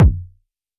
RDM_Copicat_SY1-Kick01.wav